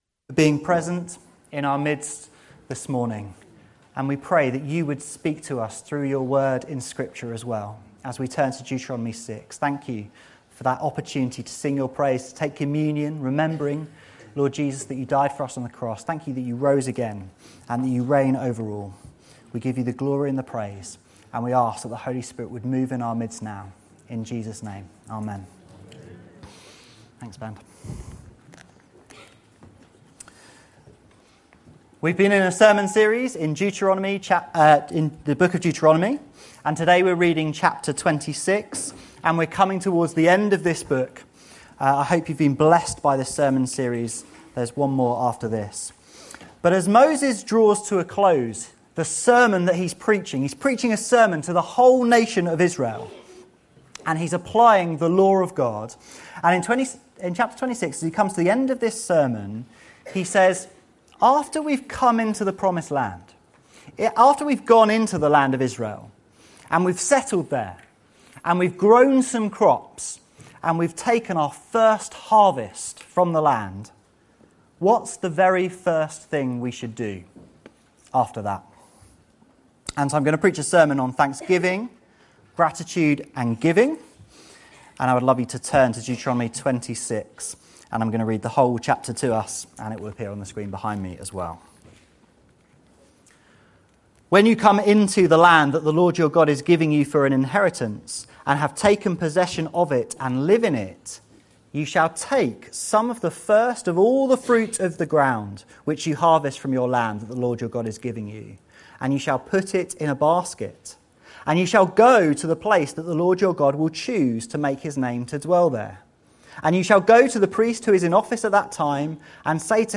Opening Prayer Being present in our midst this morning.